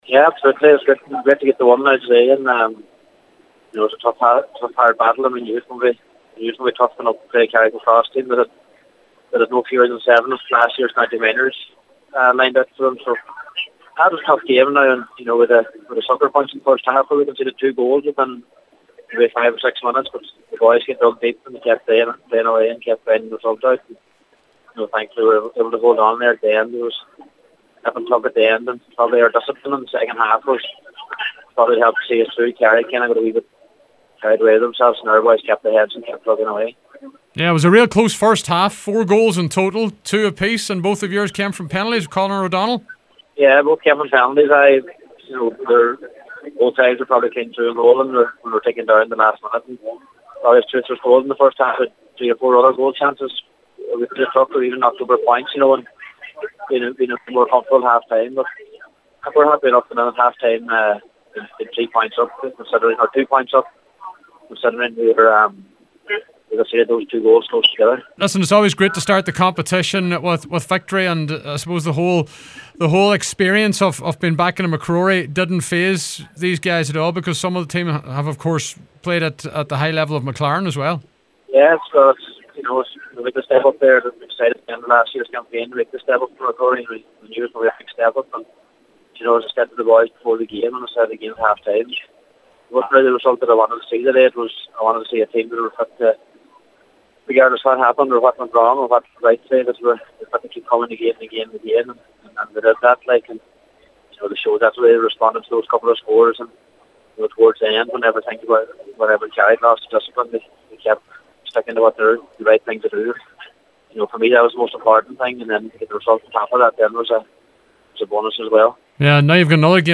Manager Colm McFadden was pleased with how his side performed…